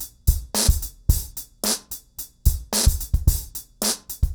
RemixedDrums_110BPM_03.wav